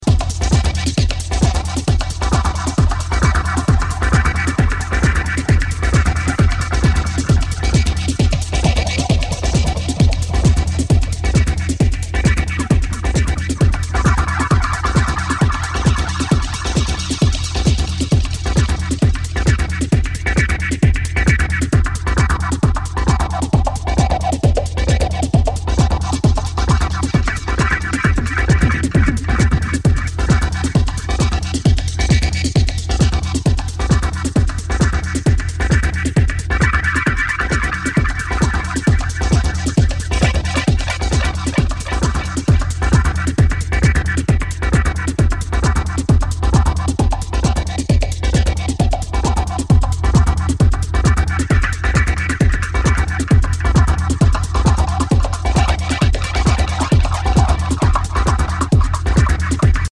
música Techno